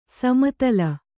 ♪ sama tala